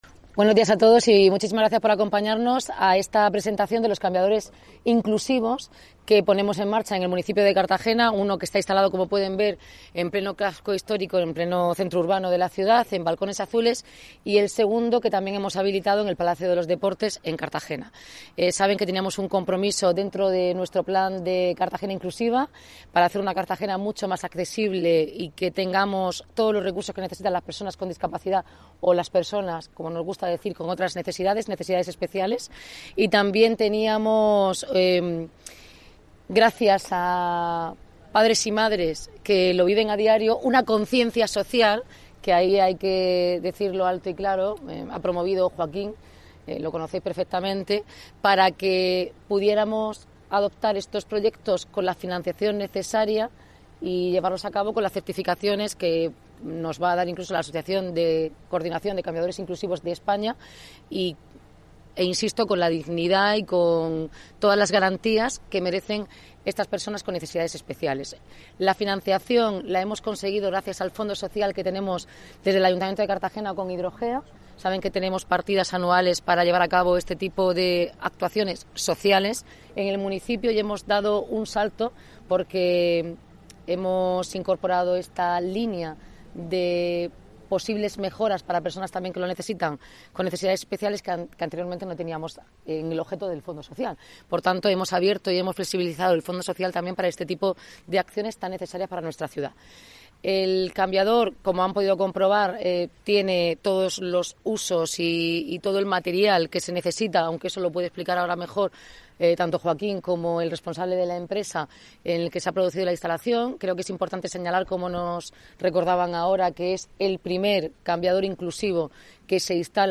Enlace a Declaraciones de Noelia Arroyo